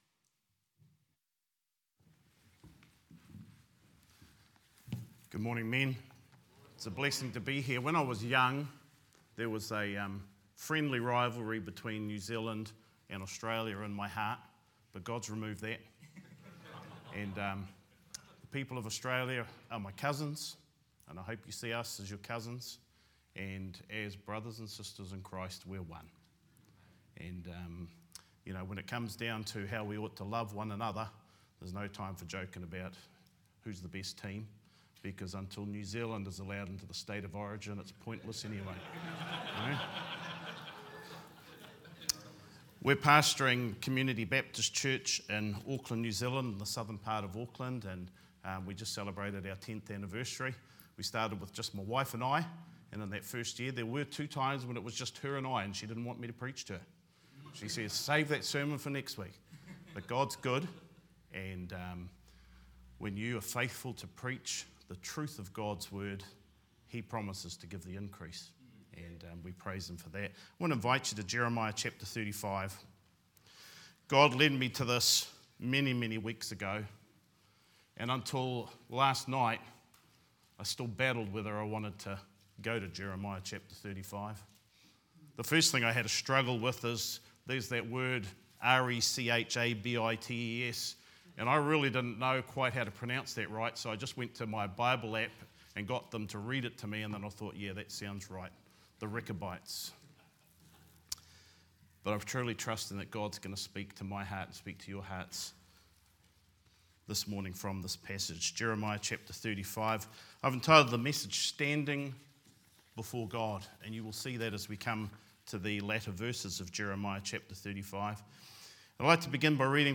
Leadership Conference 2024
Guest Speaker